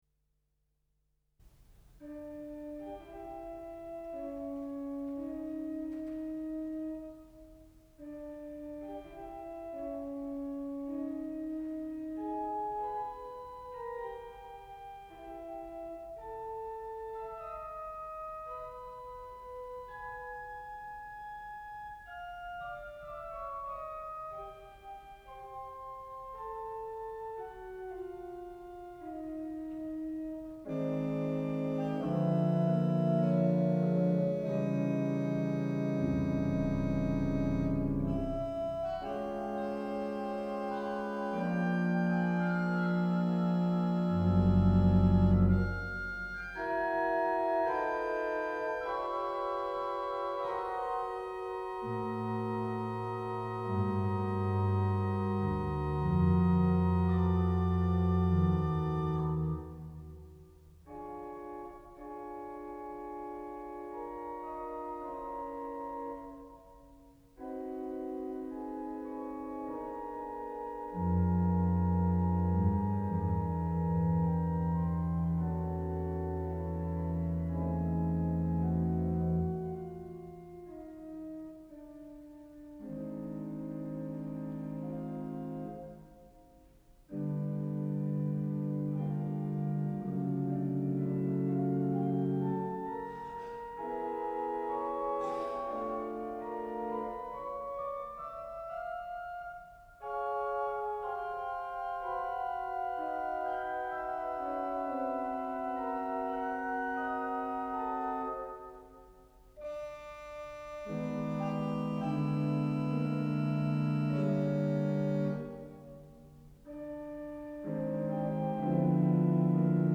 Orgel des Wiener Funkhauses